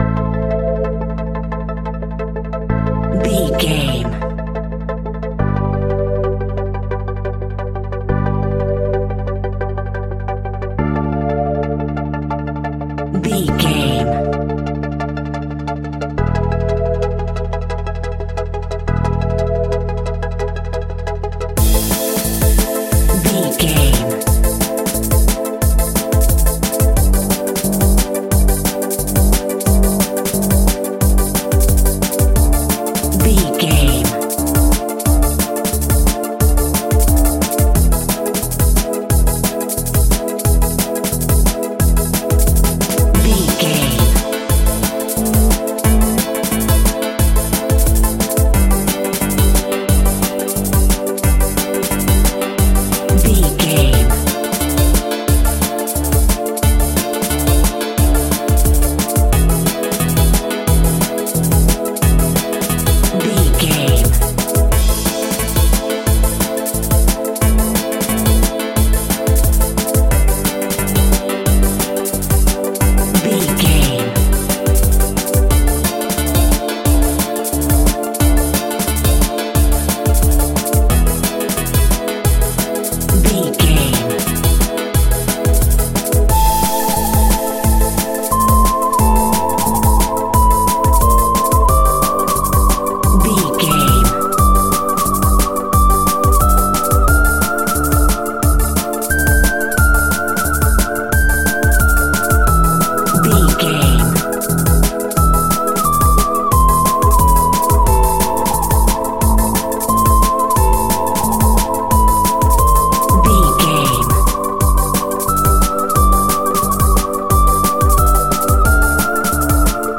Aeolian/Minor
Fast
groovy
uplifting
futuristic
driving
energetic
repetitive
drum machine
synthesiser
organ
electronic
sub bass
synth leads
synth bass